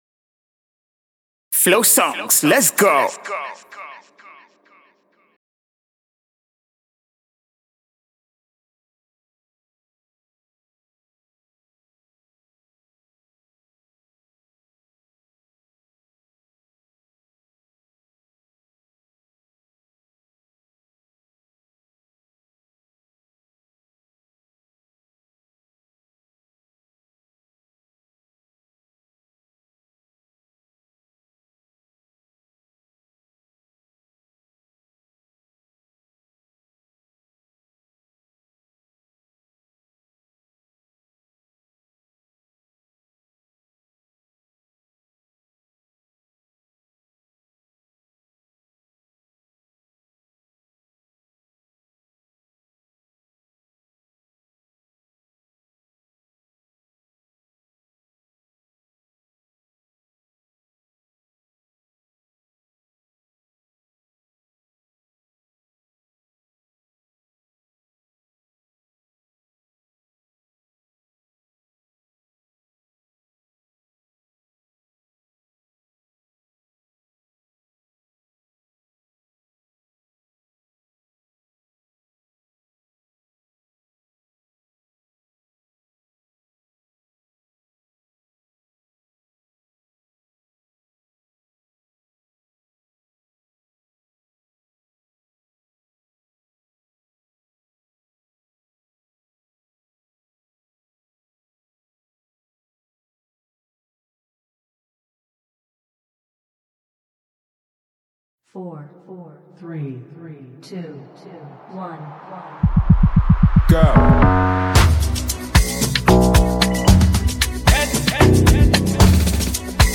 AFROPOP  and Afrobeat